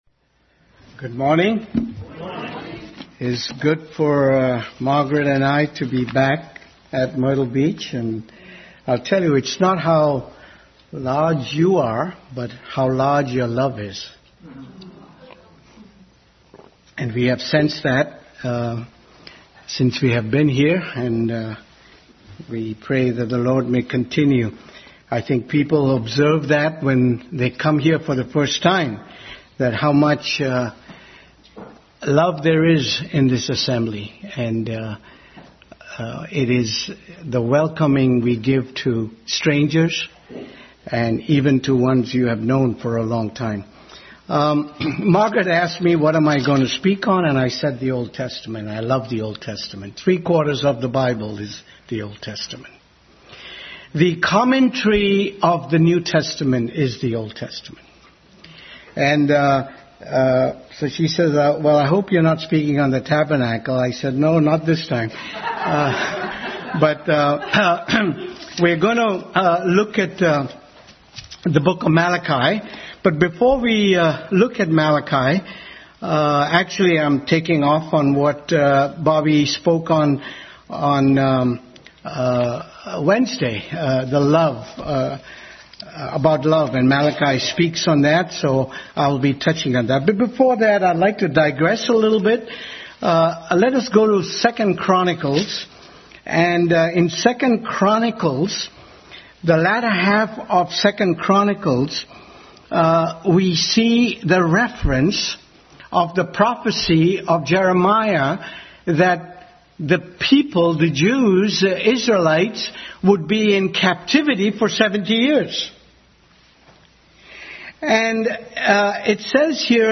Malachi Service Type: Family Bible Hour Bible Text